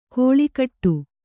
♪ kōḷikaṭṭu